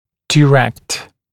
[dɪ’rekt] [daɪ-][ди’рэкт] [дай-]прямой; направлятьdirected направленный